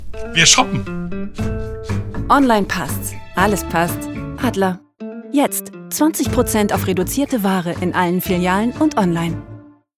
Gekürztes Audio eines TV/Internet Spots
Commercial (Werbung)